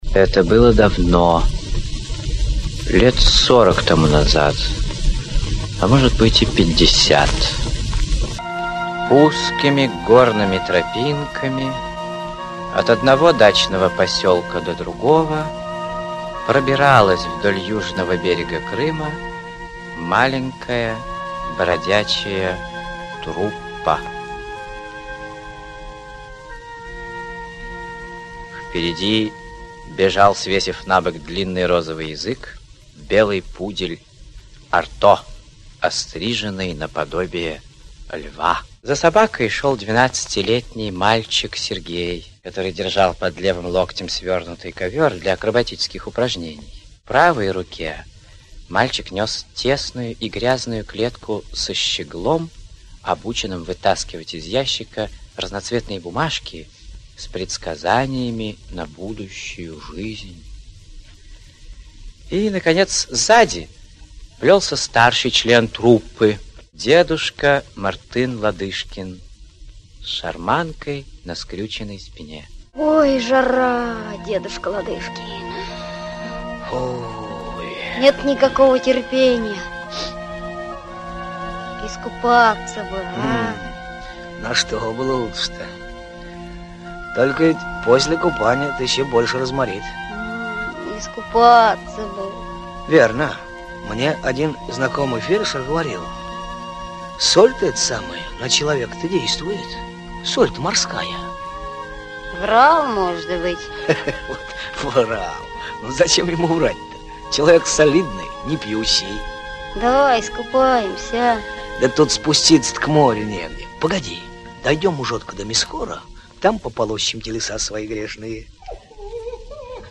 Аудиокнига Белый пудель, Allez!